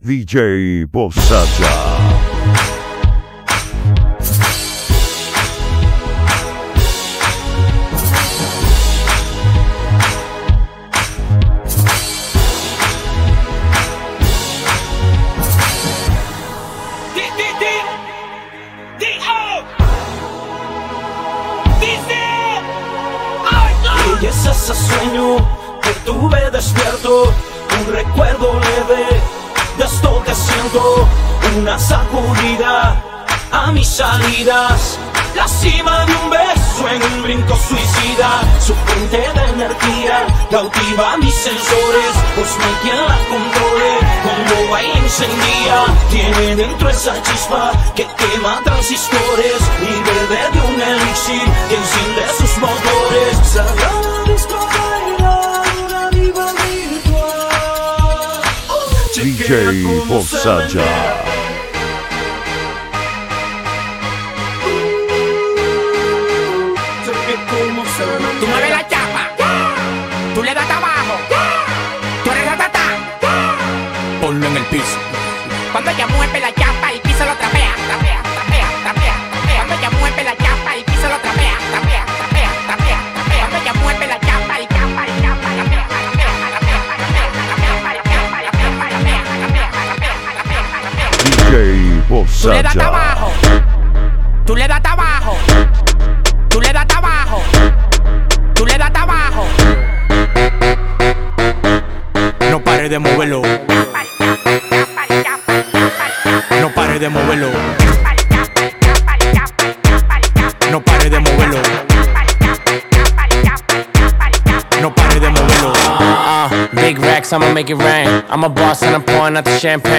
BPM: 119 Key: 1A Artistas/Temas Incluidos